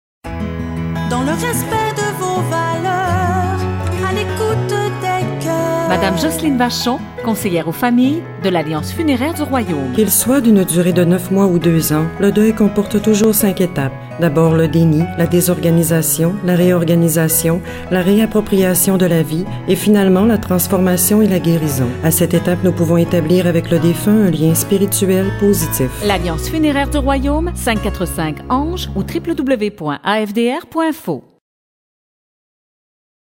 Nos capsules radio